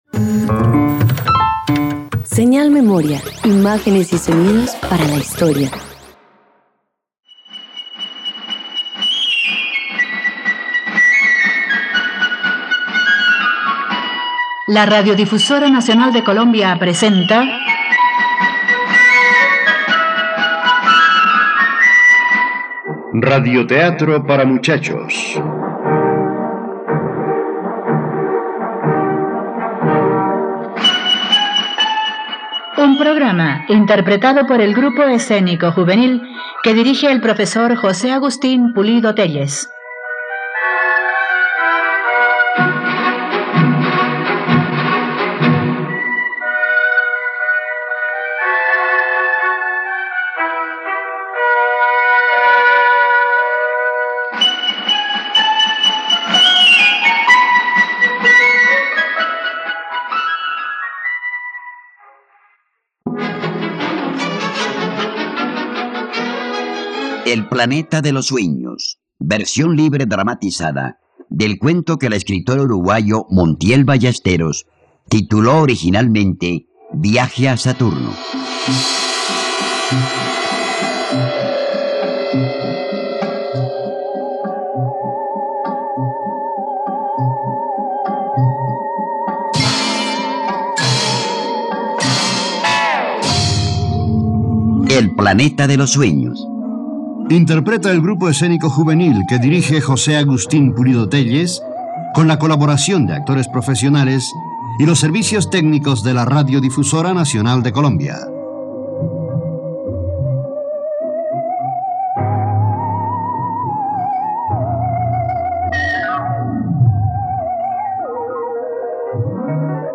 El planeta de los sueños - Radioteatro dominical | RTVCPlay